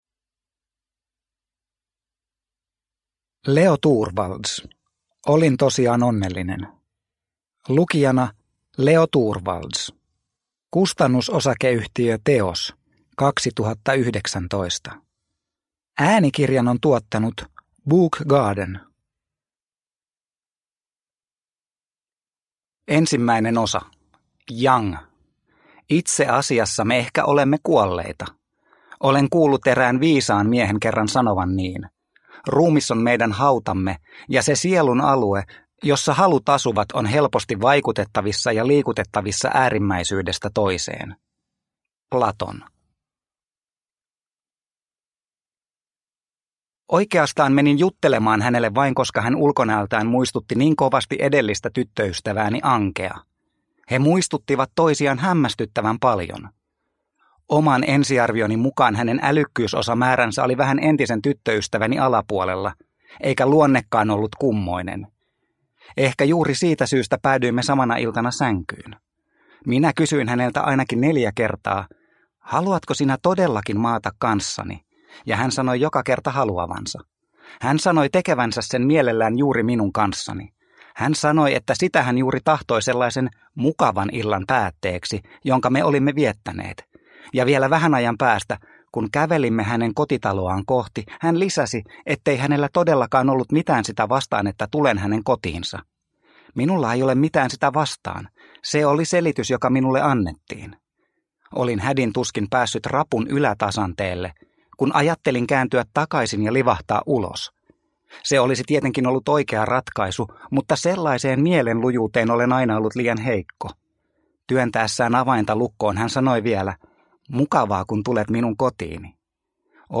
Olin tosiaan onnellinen – Ljudbok – Laddas ner